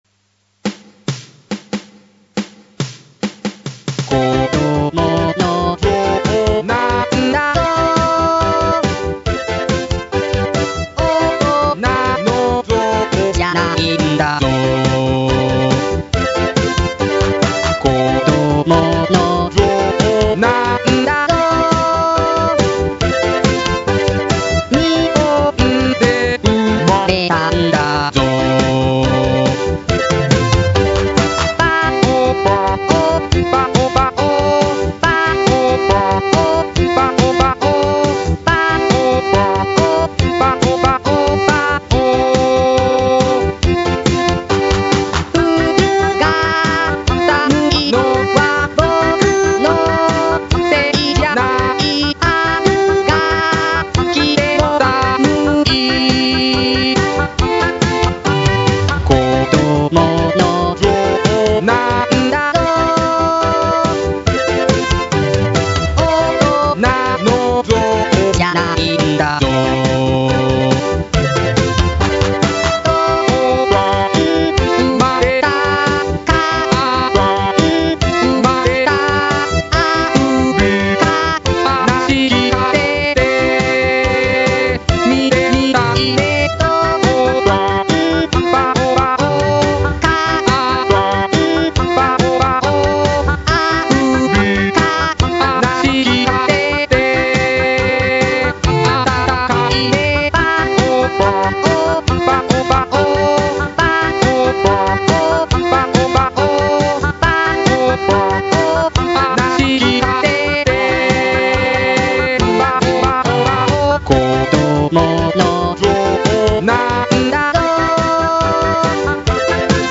デモ曲
男性ボーカル（標準添付）